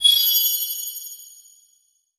magic_light_bubble_03.wav